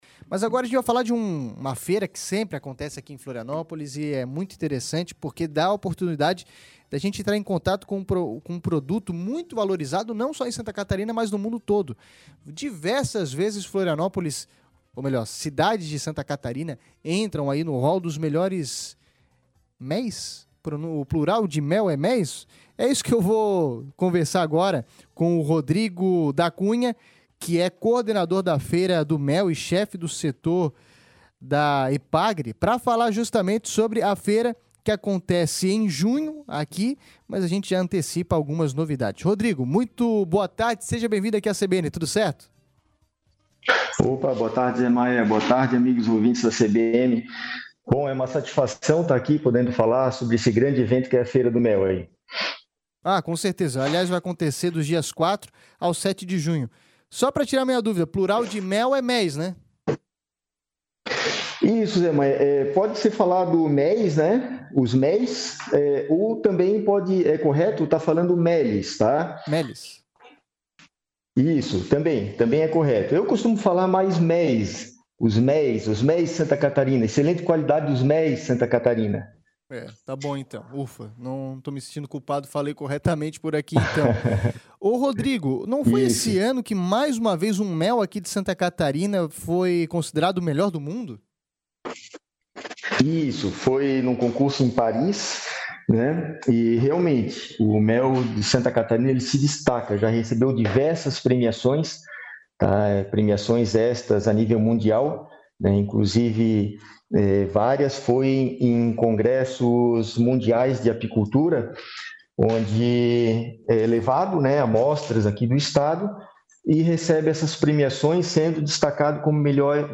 Feira do Mel em Florianópolis deve ser a maior edição de todas; ouça a entrevista